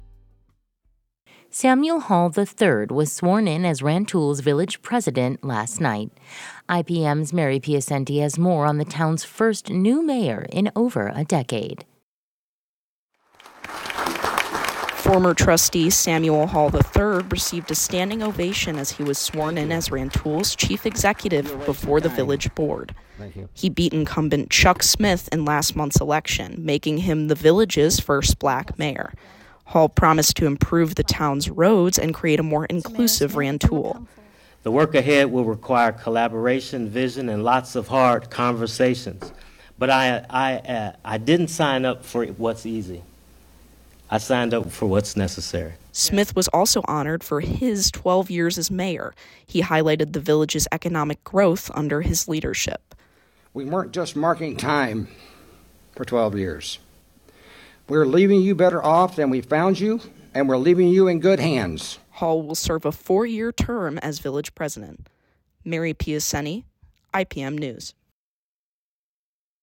RANTOUL — Former District 5 Village Trustee Samuel Hall III was sworn in as the first Black village president of Rantoul during a special board meeting at Village Hall Tuesday evening.